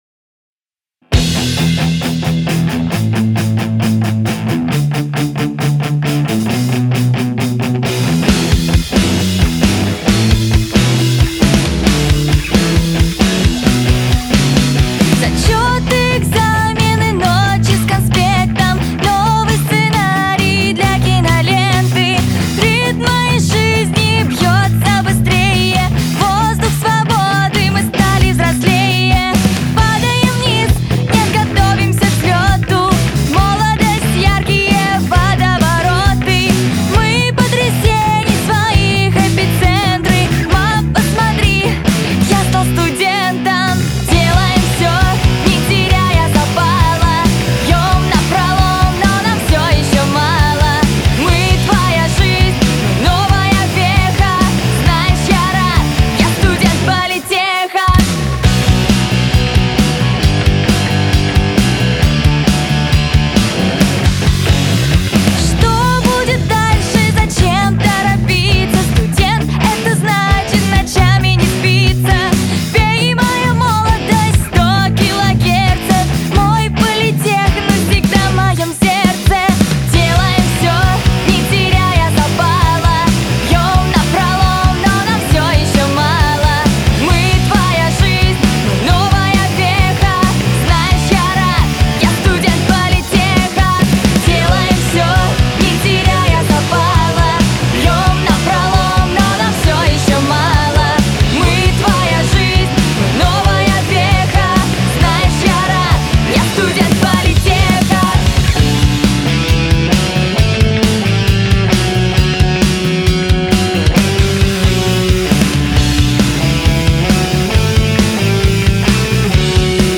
Так, в октябре на барнаульской студии звукозаписи, и родился наш студенческий гимн уже как полноценный трек.
Далеко не у каждого, даже очень большого, университета в стране, есть свой студенческий гимн, а уж таким огненным вообще мало кто может похвастаться!
вокал
гитара/бэк-вокал
бас-гитара
ударные